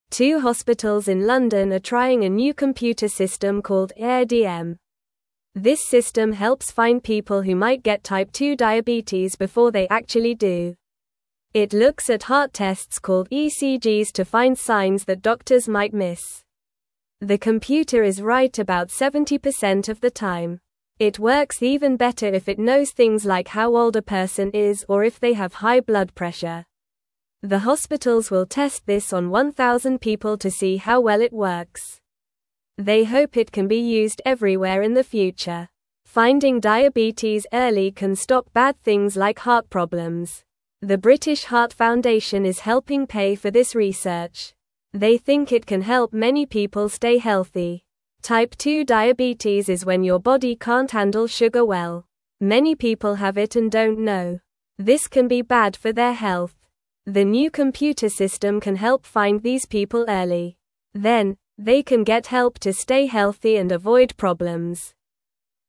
Normal
English-Newsroom-Beginner-NORMAL-Reading-New-Computer-Helps-Find-Diabetes-Early-in-People.mp3